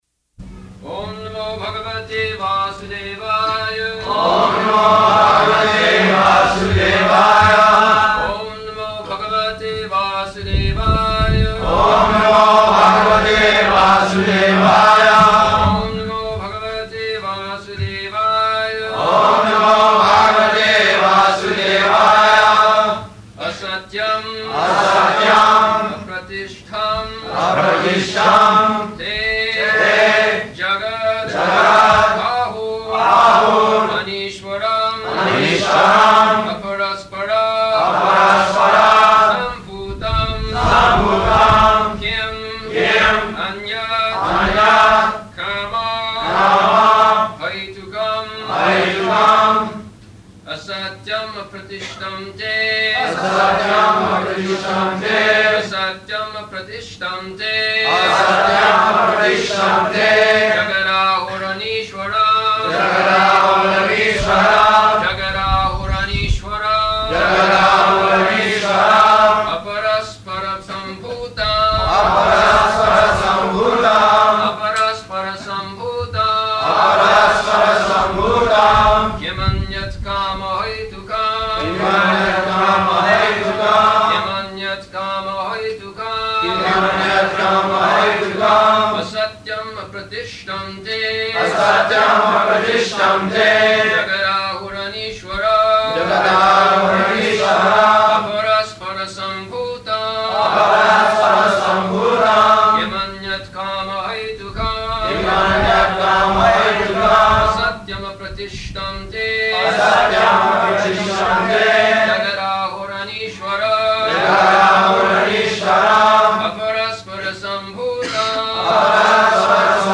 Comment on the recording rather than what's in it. February 4th 1975 Location: Honolulu Audio file